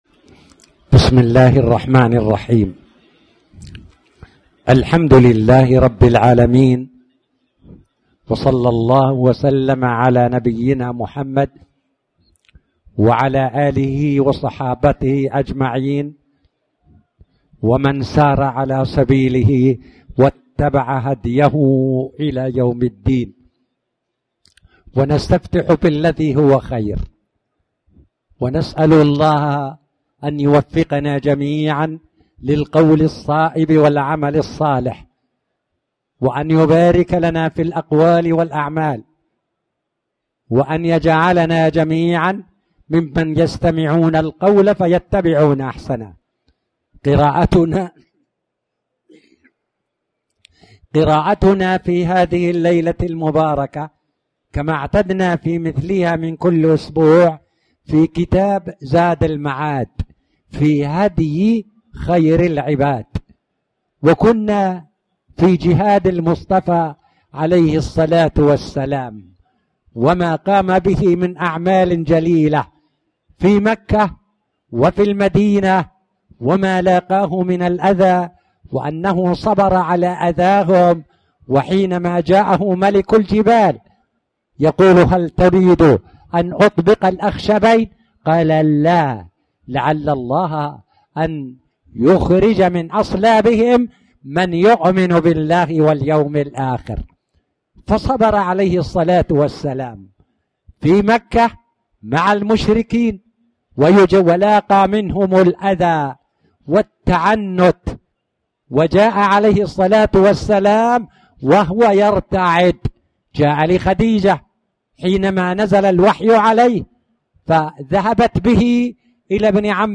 تاريخ النشر ١٥ محرم ١٤٣٩ هـ المكان: المسجد الحرام الشيخ